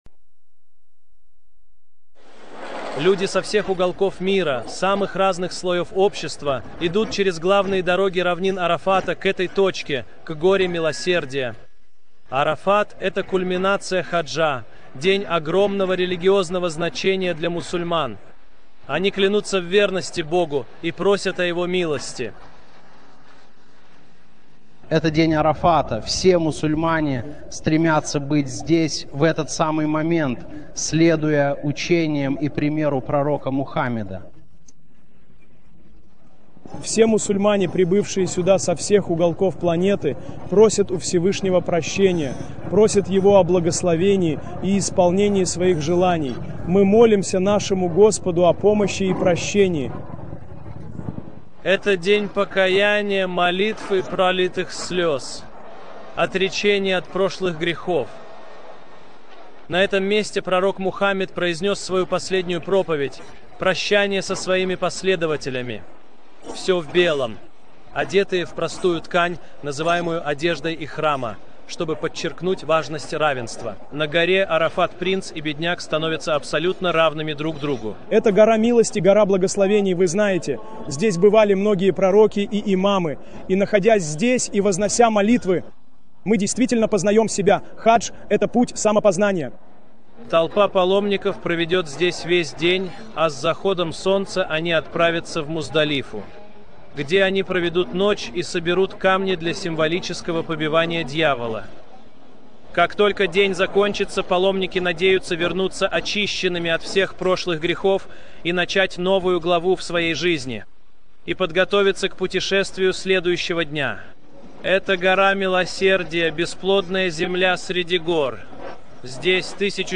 Описание: Это видео — новостной репортаж телеканала «Аль-Джазира» о достоинствах хаджа.